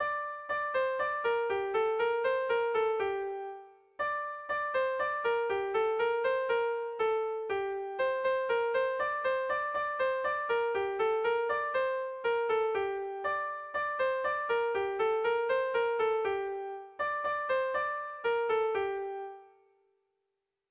Irrizkoa
Hamarreko berdina, 8 puntuz eta 6 silabaz (hg) / Zortzi puntuko berdina (ip)